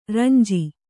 ♪ ranji